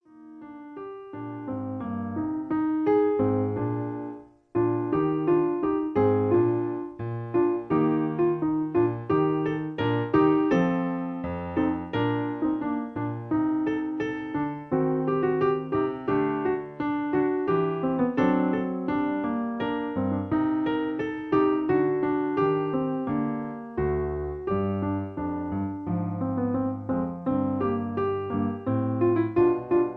Original key (E flat) Piano Accompaniment